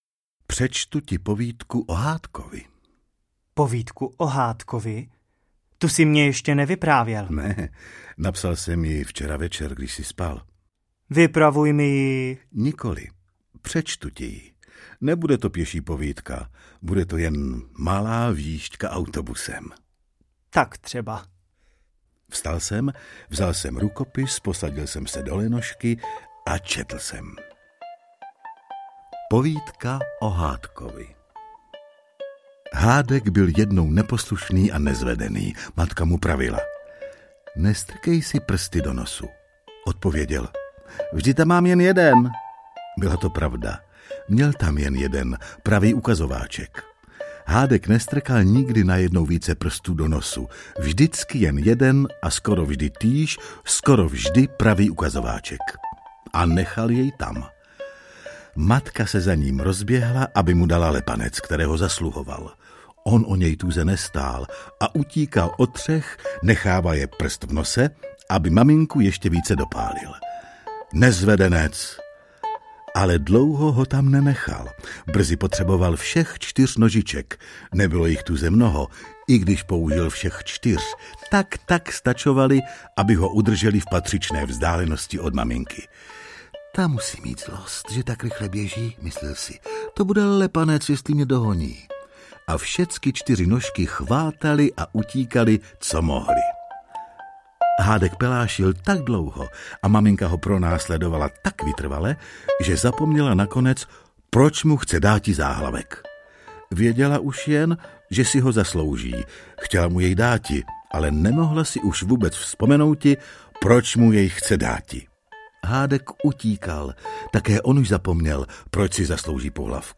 Renouškovy povídky audiokniha
Ukázka z knihy